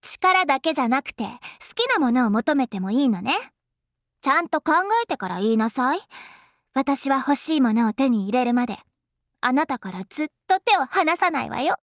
ユーノの突破ボイスエロン🦕
play_favor_word_younuo_sys_rankup04.mp3